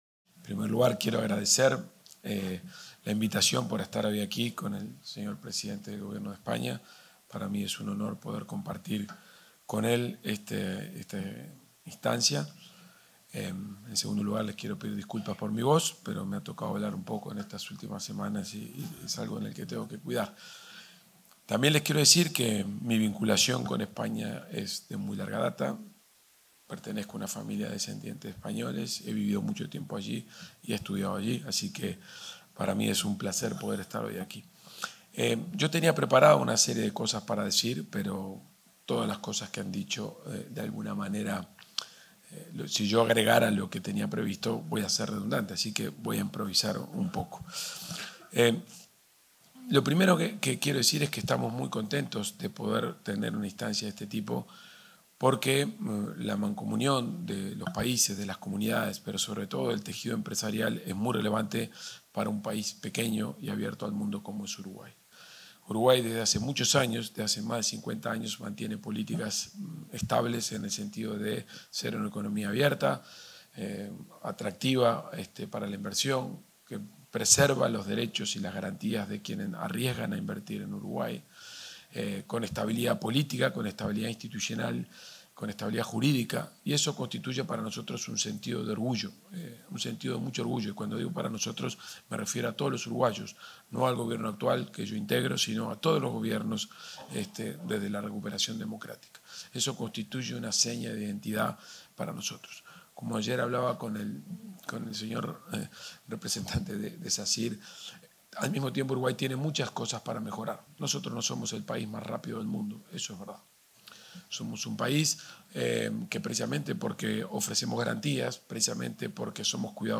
Palabras del ministro de Economía y Finanzas, Gabriel Oddone
El ministro de Economía y Finanzas, Gabriel Oddone, expuso ante empresarios españoles y uruguayos, durante la visita a nuestro país del presidente de